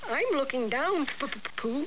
The voices of Pooh and his friends